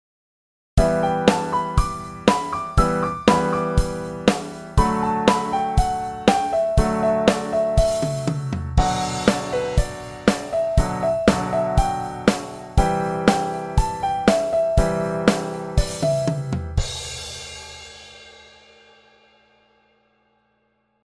Rock Piano Soloing
In the following two examples, notes from the E minor pentatonic scale are played over a chord simple progression in the same key.
The second example mainly features eighth notes, but always comes to rest between the different phrases.
The lines in bar 3 and 5 contain an additional F#, which is part of the underlying D major and B minor chords and can easily be added to the E minor pentatonic.
rock piano pentatonic soloing 2
rock-piano-pentatonic-soloing_2.mp3